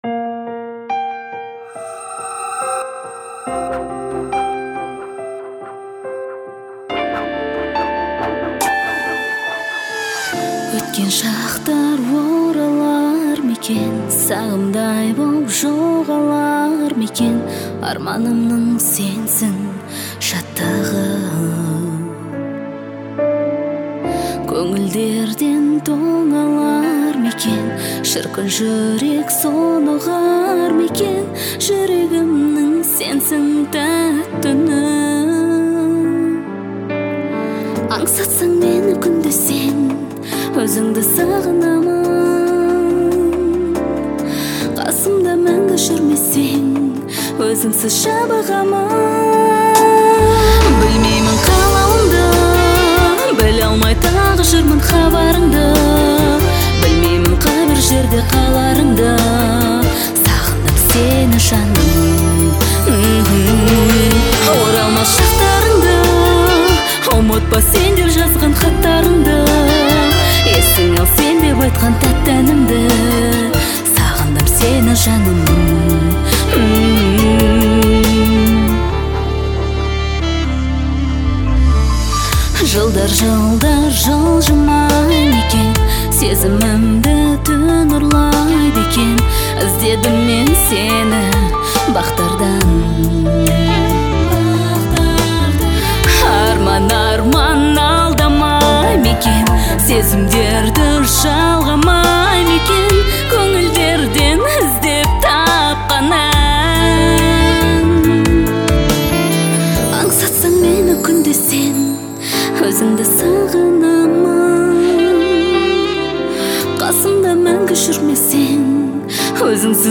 это трогательное произведение в жанре казахской поп-музыки.